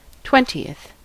Ääntäminen
IPA : /ˈtwɛn.ti.əθ/